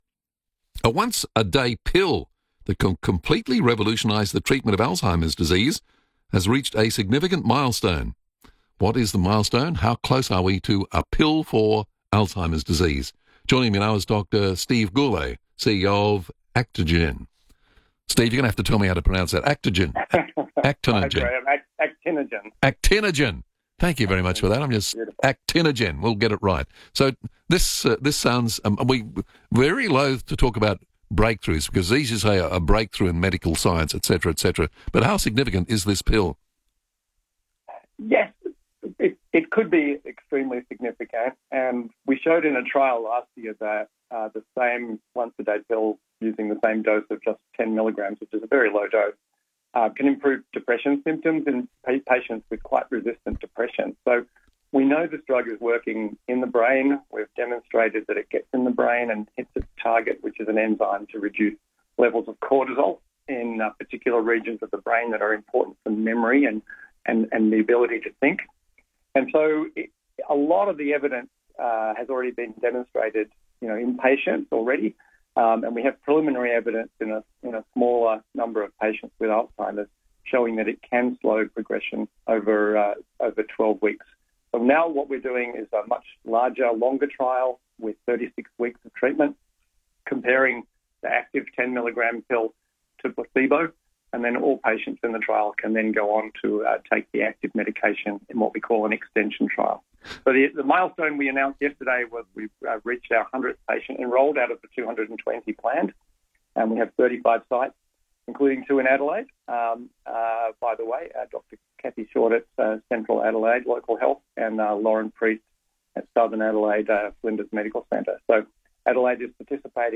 FIVEAA Adelaide radio interview